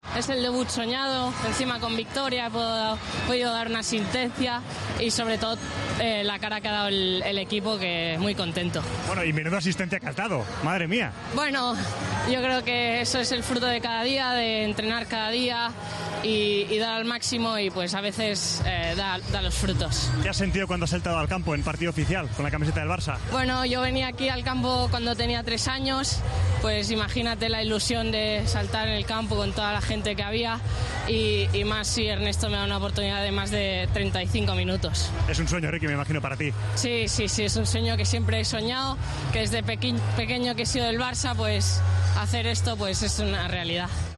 El canterano del Barcelona habló en Gol tras debutar con el primer equipo y, después, en zona mixta: "Ha sido un debut soñado".